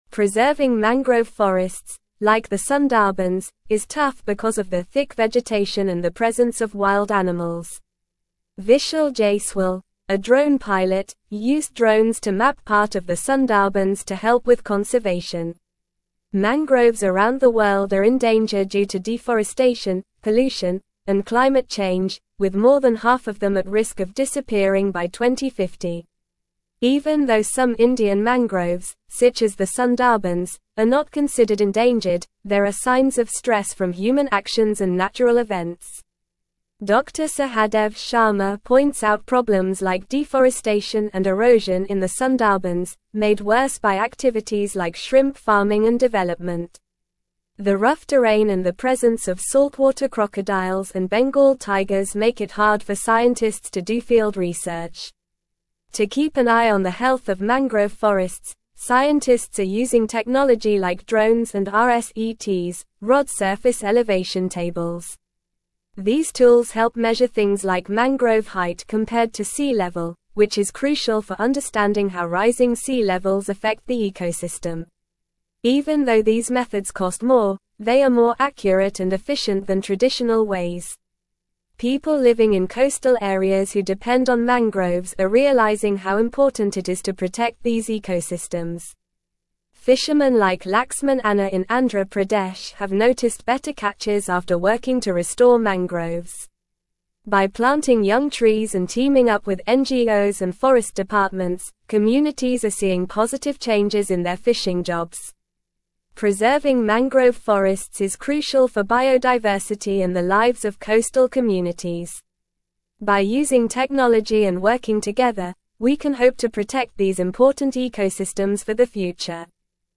Normal
English-Newsroom-Upper-Intermediate-NORMAL-Reading-Mapping-Sundarbans-Drones-Preserve-Worlds-Largest-Mangrove-Forest.mp3